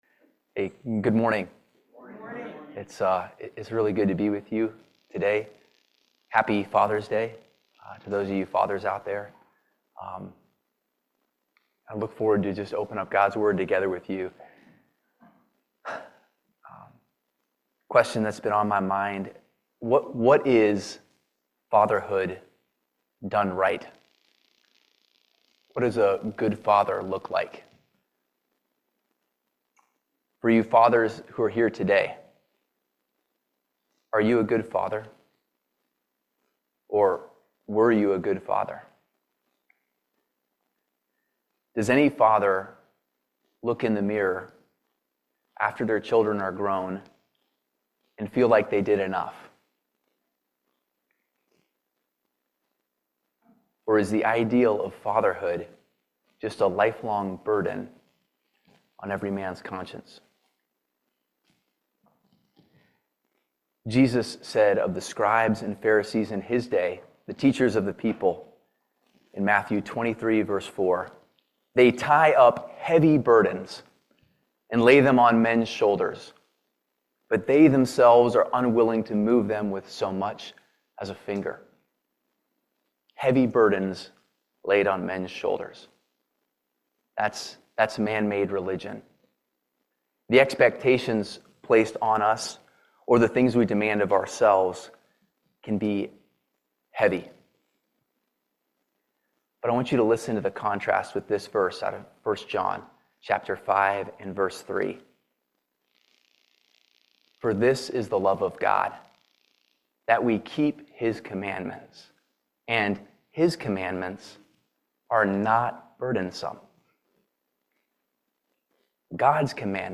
Ephesians 6:1-4 Service Type: Family Bible Hour God’s Word teaches men how to be godly fathers.